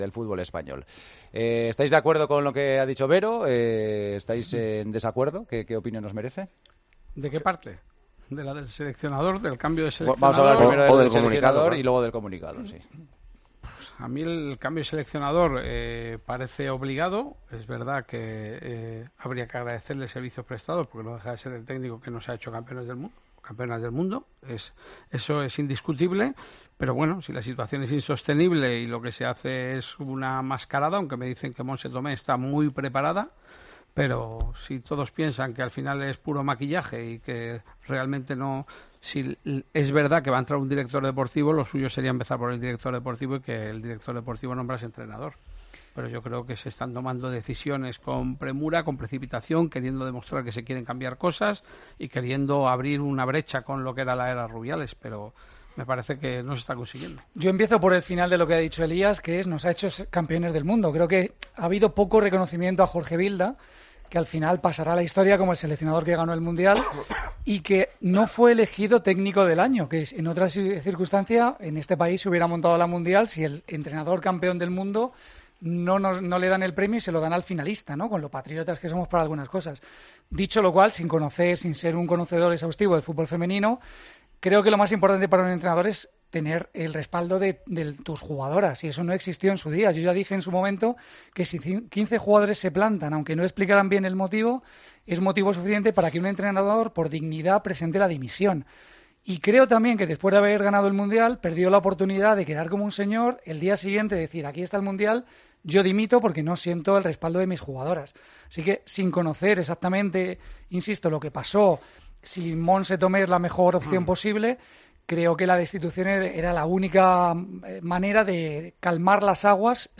Los tertulianos de El Partidazo de COPE valoran los cambios en la RFEF